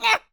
Sfx Player Boulder Pickup Sound Effect
sfx-player-boulder-pickup-1.mp3